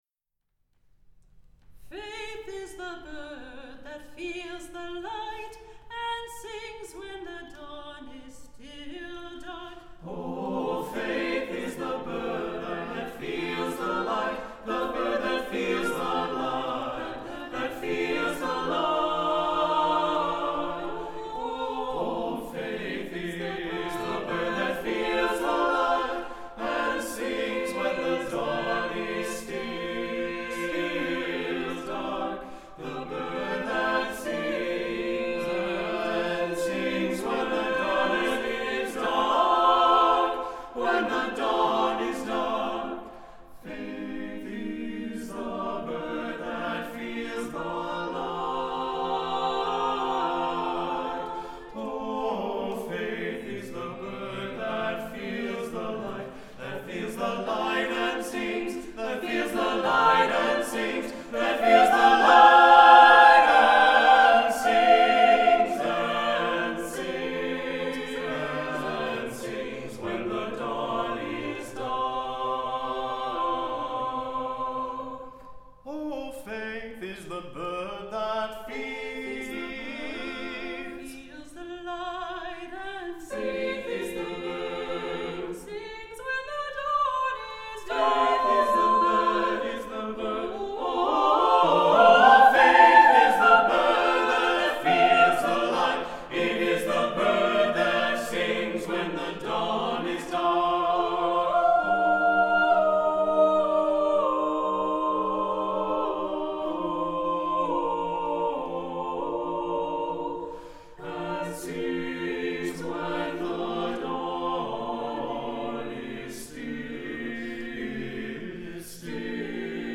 SATB a cappella